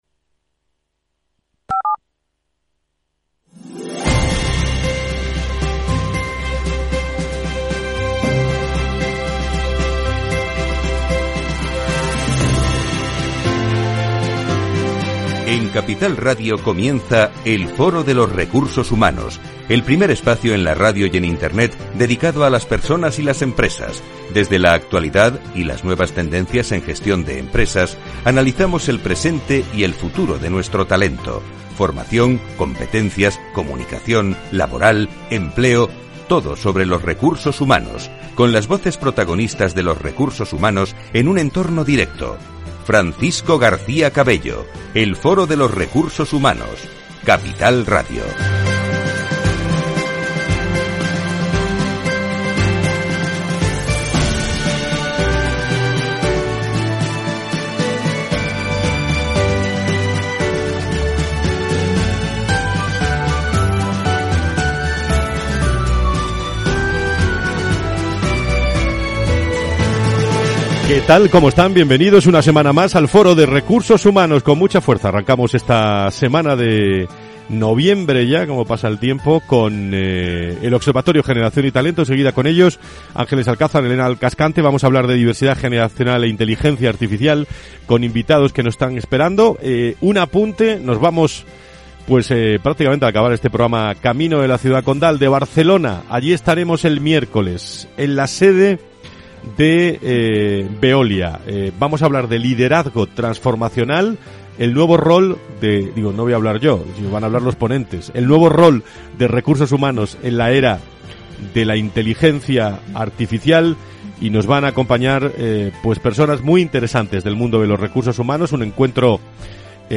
Estos tres invitados han compartido sus primeras valoraciones e impresiones tras la primera reunión de trabajo que ha mantenido este comité de expertos, y que marca el punto de partida de este nuevo ciclo de trabajo del Observatorio Generación &Talento.